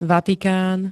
Vatikán [-t-] -nu m.
Zvukové nahrávky niektorých slov